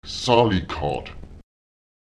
Lautsprecher zálekát [Èsaùlekaùt] das Rechengerät